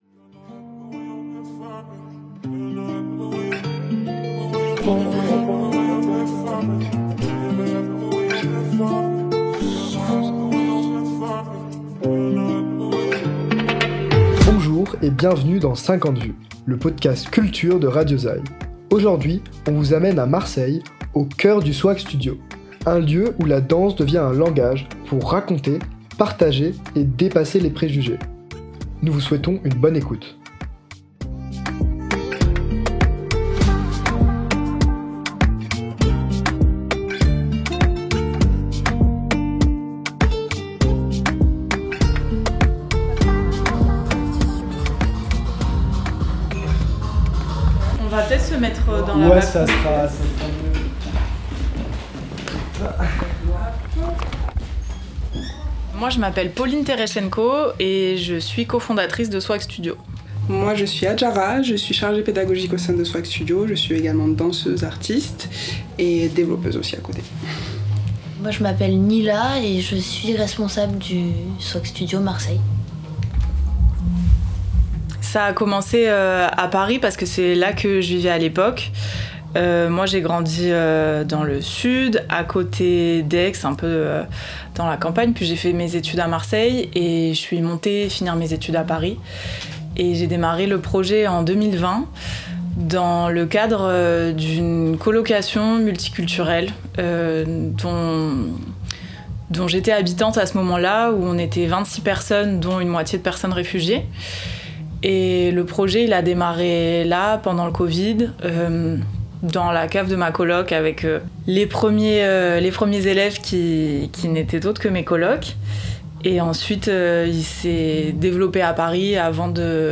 À travers des interviews et des reportages radio, on vous emmène directement au contact d’acteurs culturels pour écouter, comprendre et donner la parole à des voix qu’on entend trop peu, même si c’est pour faire 50 vues.